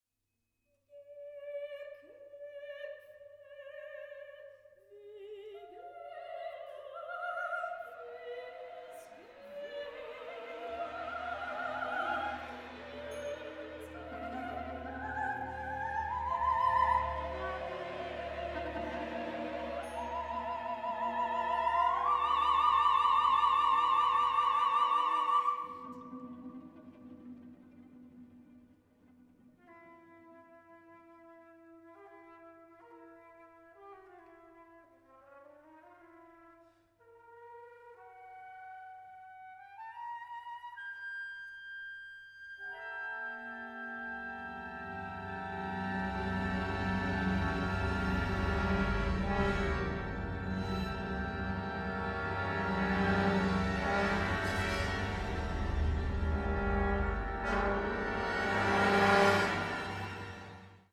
for two sopranos and orchestra